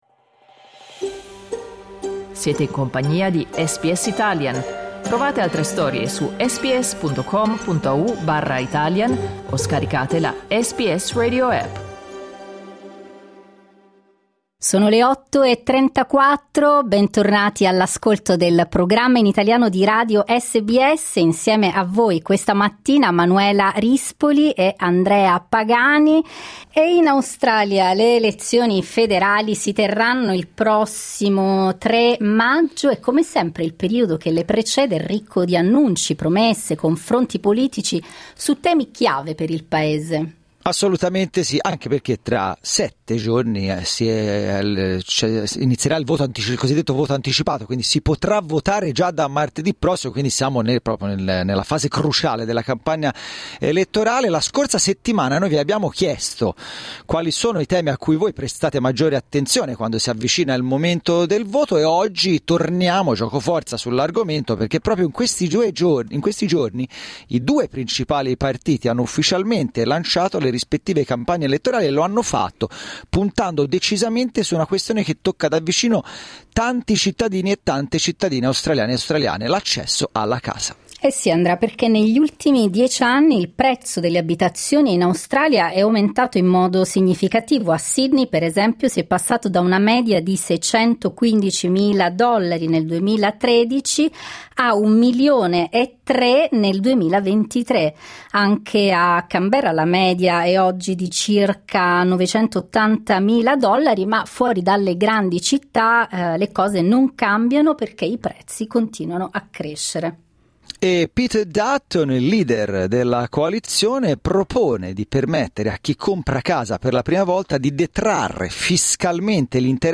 Clicca sul tasto "play" in alto per ascoltare il dibattito